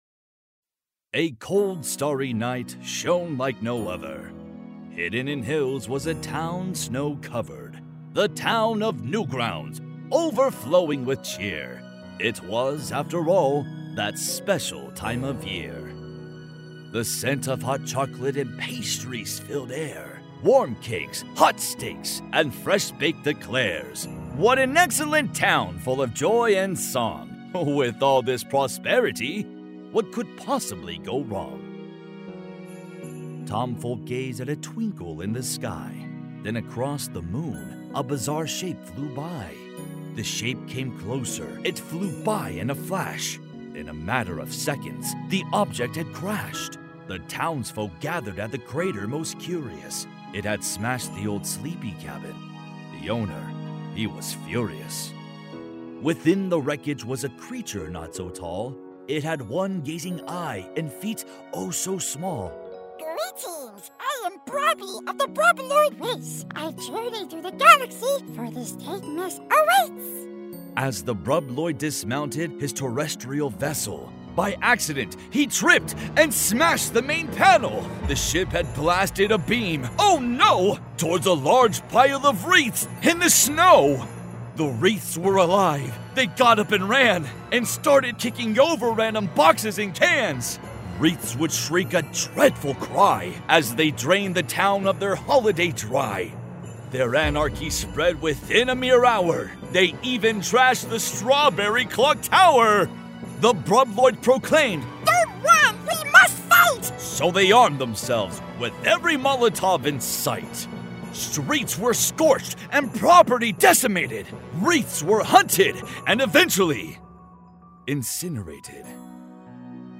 I simply put my vocal chords to work and narrated the best I could!
Lead Vocals
Spoken Word